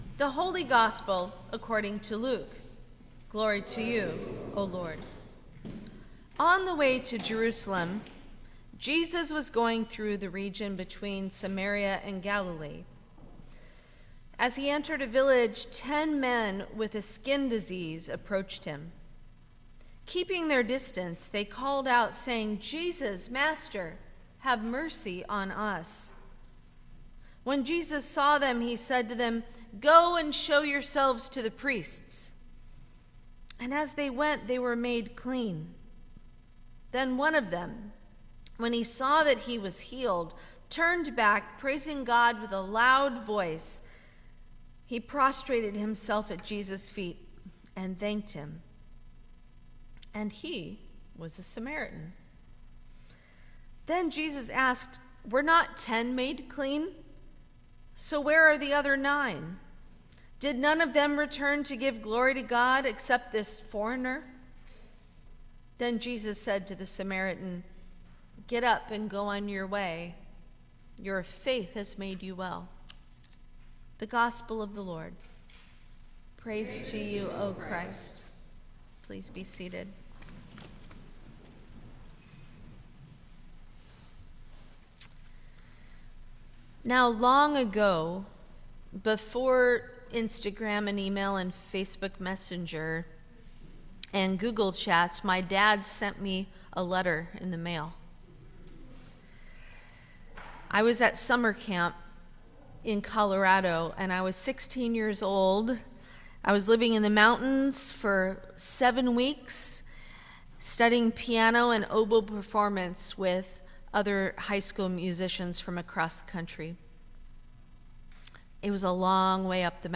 Sermon for the Eighteenth Sunday after Pentecost 2025